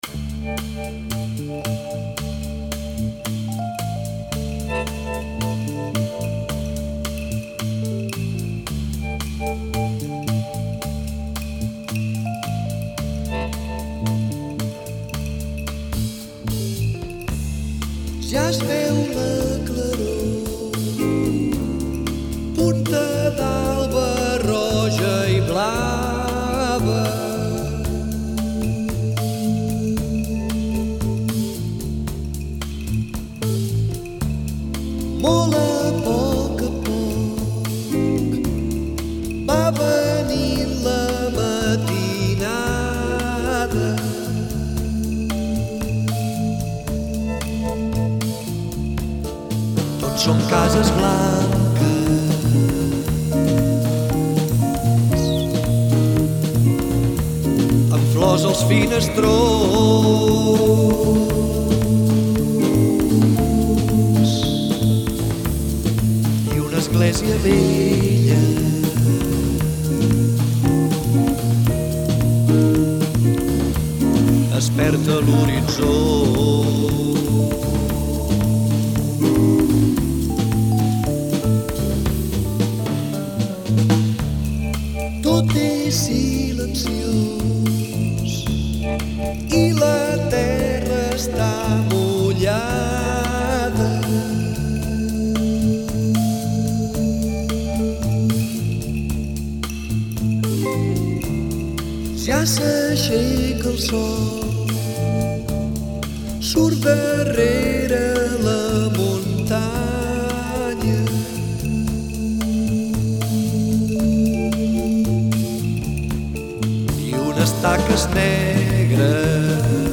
Great Spanish / Catalan jazz
Nice Fender Rhodes here, and it's quite groovy !